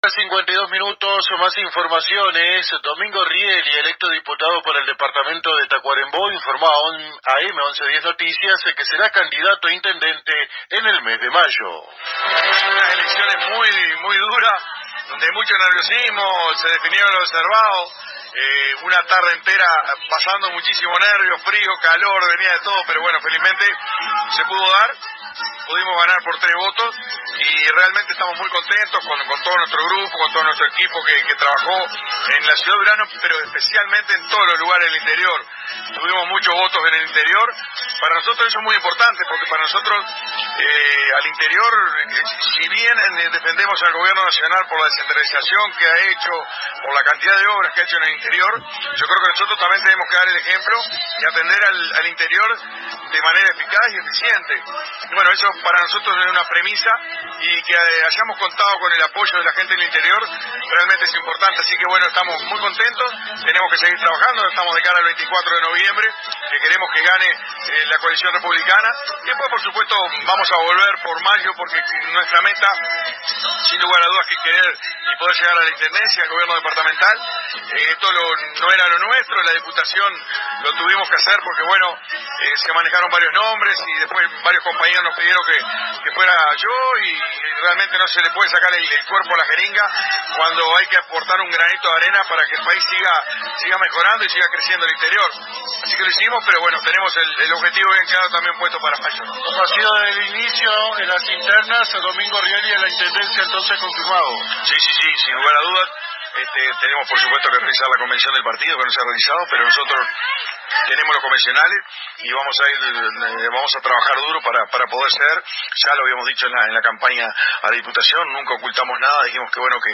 Durante la trasmisión que hicieron los colegas del Grupo Toros AM y FM de nuestra ciudad en el pasado Festival del Rosedal, tuvieron la oportunidad de conversar con el Secretario General de la Intendencia, Domingo Rielli, recientemente electo como Diputado por Durazno, quien anunció sus aspiraciones a postularse a la jefatura del departamento en los comicios de mayo del 2025.